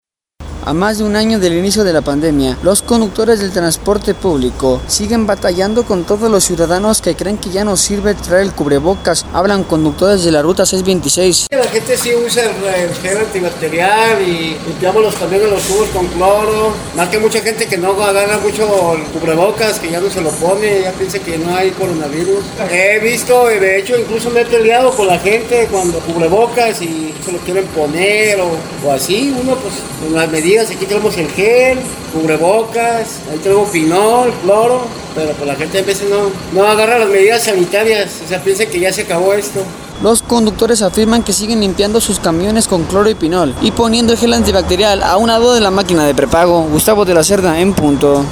A más de un año del inicio de la pandemia, los conductores del transporte público siguen batallando con todos los ciudadanos que creen que ya no sirve traer el cubrebocas, hablan conductores de la ruta 626